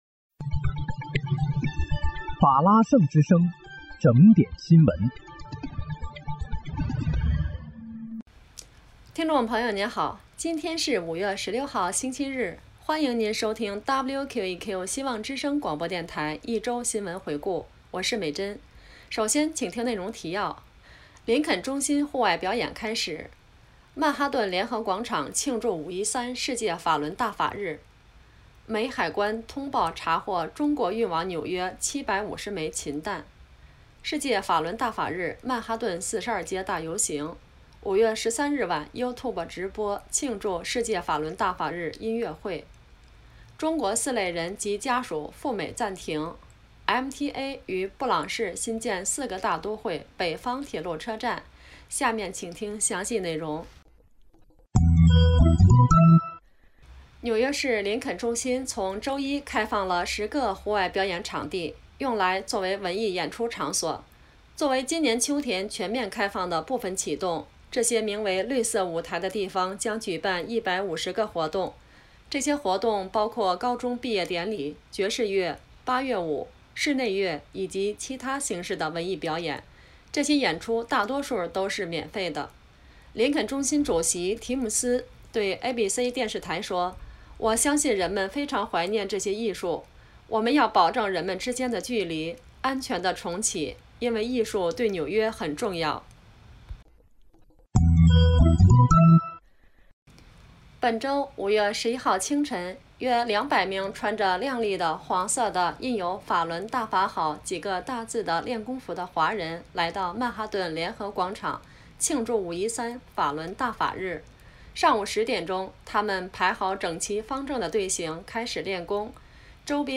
5月16日(星期日）一周新闻回顾